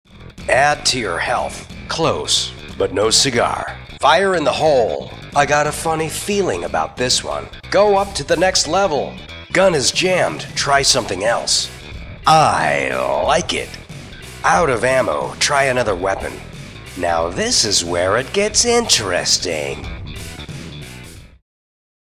Game Voices (Various Characters)